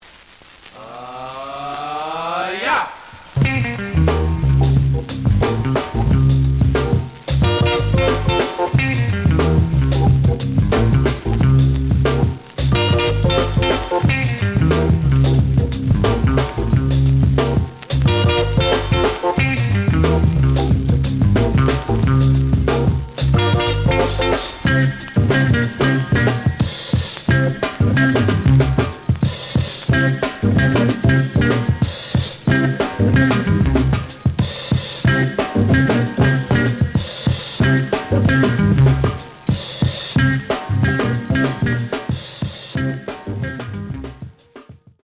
The audio sample is recorded from the actual item.
●Genre: Funk, 60's Funk
Noticeable noise on both sides.